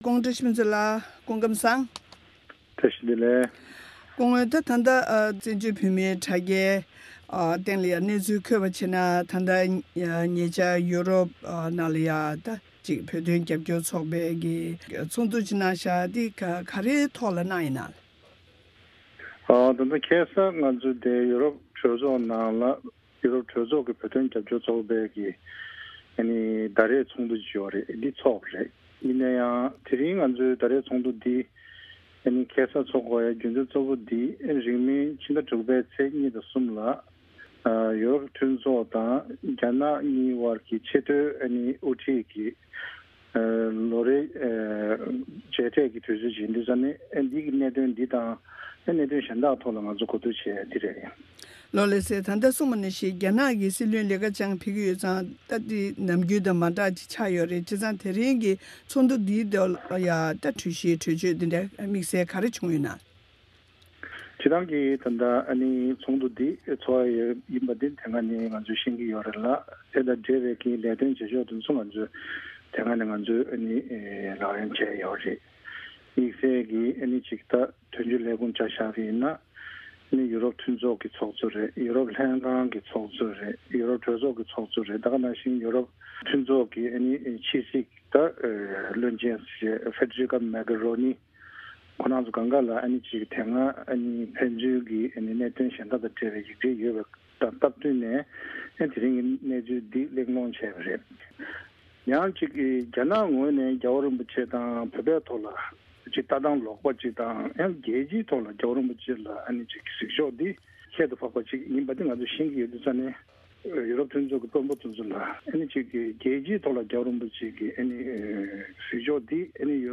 འབྲེལ་ཡོད་མི་སྣར་གནས་འདྲི་ཞུས་པ་དེ་གསན་རོགས་གནང་།